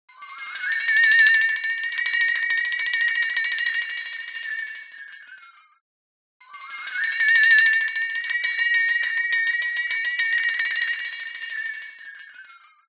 Listen to the effect of time-stretching on the sound of a flexitone (a percussion instrument sometimes used to make eerie sound effects). The unmodified flexitone reconstruction is played first, followed by a stretched version.
All sound morphs and syntheses presented here were created using the open source Loris software for sound analysis, synthesis, and manipulation.